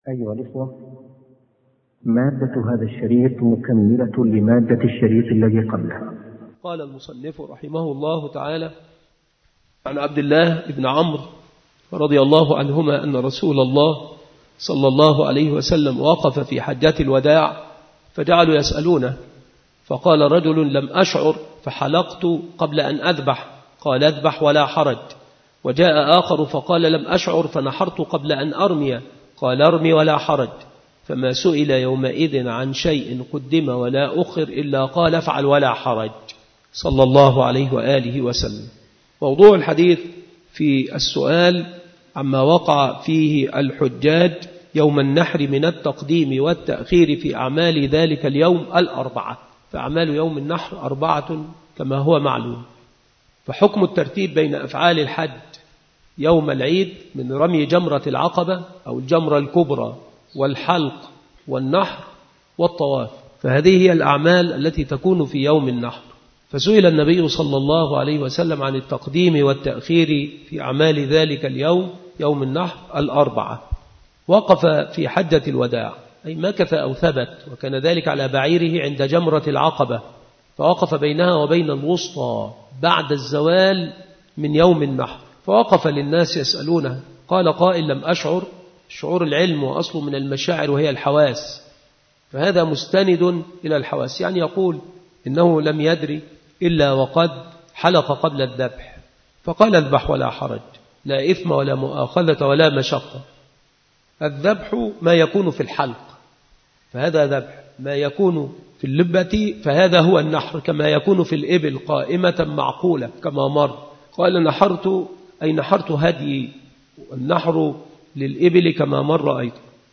مكان إلقاء هذه المحاضرة بالمسجد الشرقي بسبك الأحد - أشمون - محافظة المنوفية - مصر